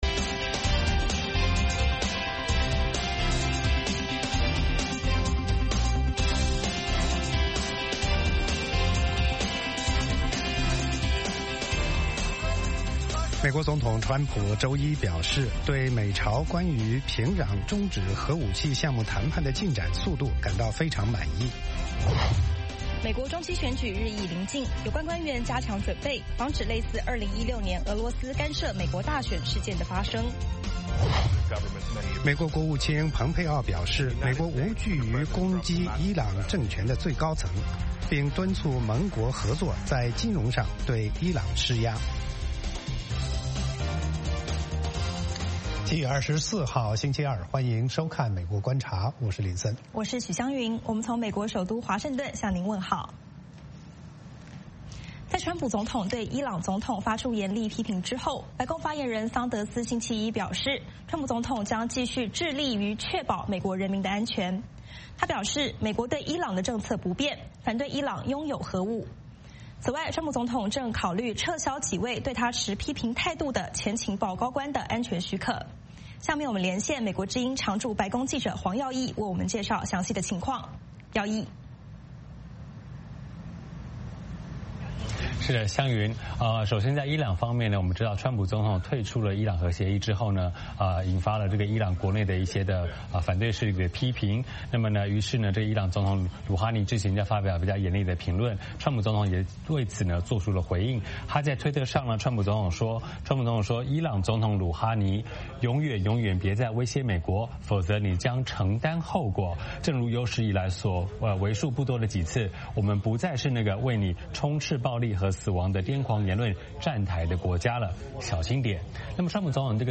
“VOA卫视 美国观察”掌握美国最重要的消息，深入解读美国选举，政治，经济，外交，人文，美中关系等全方位话题。节目邀请重量级嘉宾参与讨论。